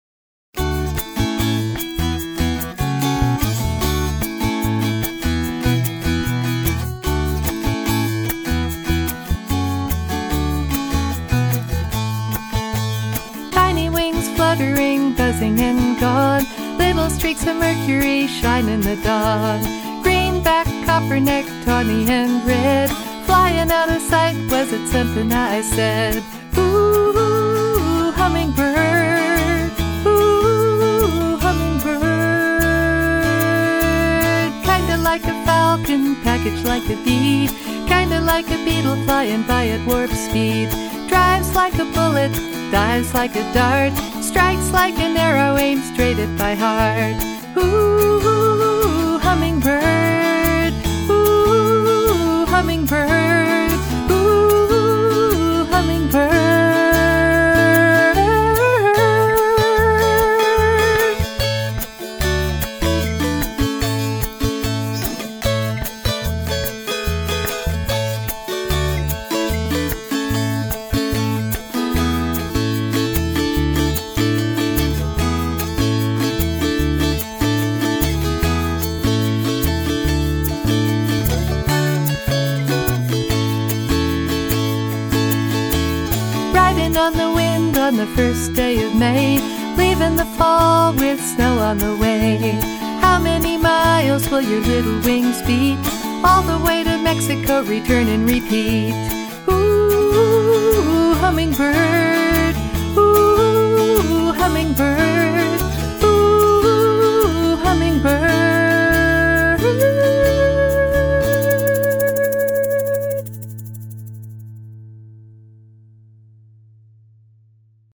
guitar, mandolin, dulcimer and cello